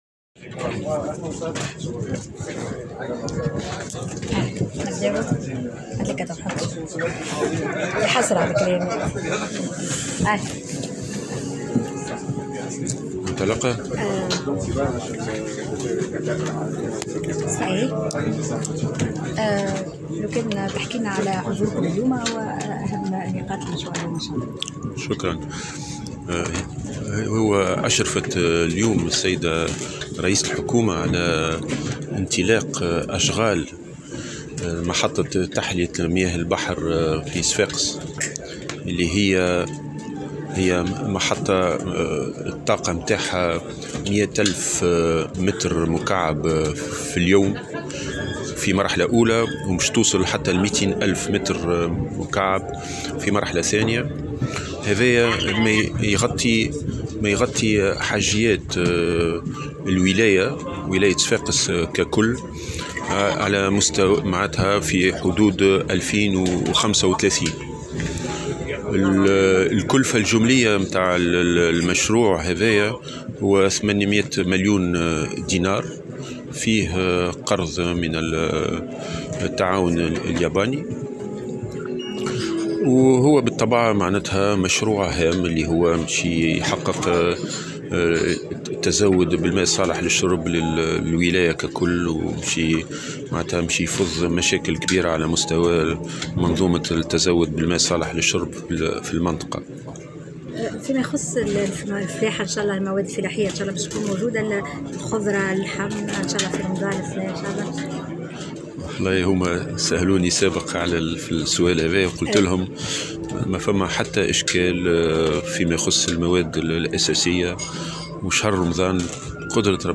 S’exprimant au micro de Tunisie Numérique, le ministre de l’Agriculture, Mahmoud Elyes Hamza a indiqué en marge de sa participation à la cérémonie de lancement du projet de construction de la station de dessalement d’eau de mer à Sfax que la station en question sera d’une capacité de production de 100 mille m³/j et atteindra jusqu’à 200 mille m³/j.